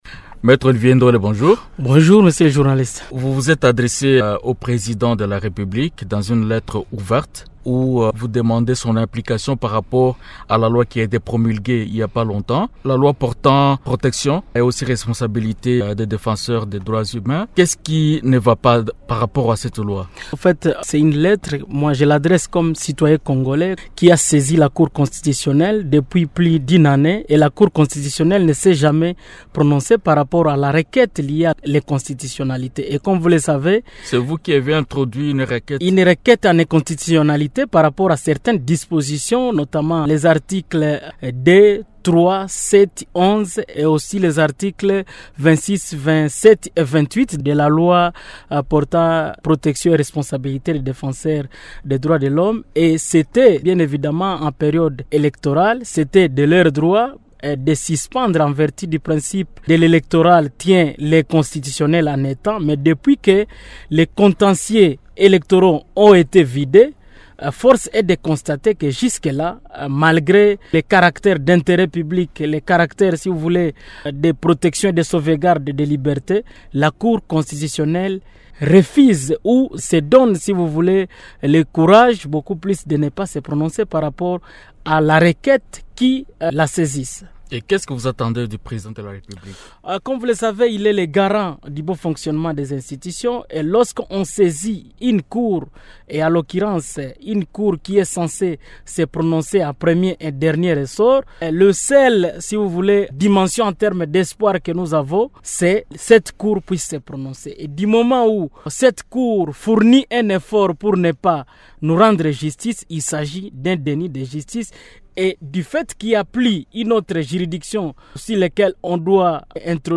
Invité de Radio Okapi, il explique avoir introduit des requêtes en inconstitutionnalité par rapport aux articles 2, 6, 11 et 26, 27 et 28 de la loi, portant protection des défenseurs des droits de l’homme.